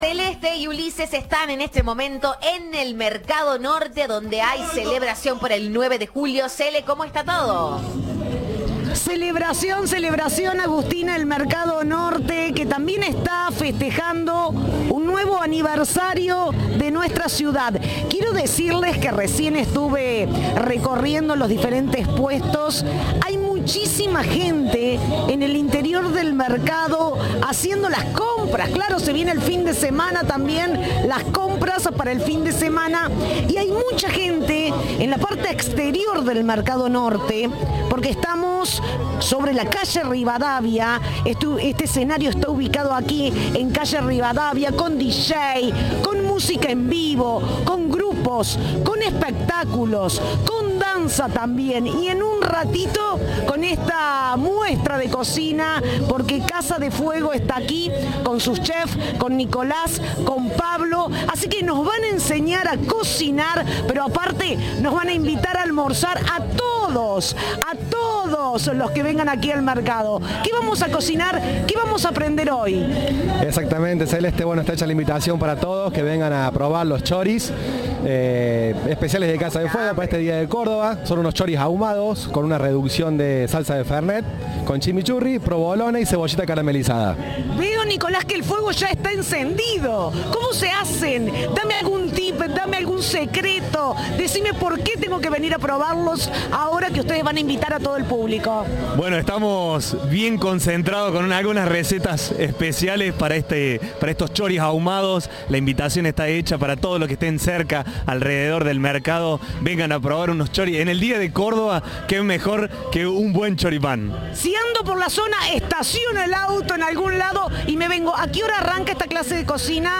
Hubo feria de flores y economía social, shows musicales, gastronomía en vivo y mucho más en la Supermanzana Mercado Norte.
Cerca del mediodía del viernes, durante la emisión del programa Siempre Juntos de Cadena 3, se presentó "La Clave Trío".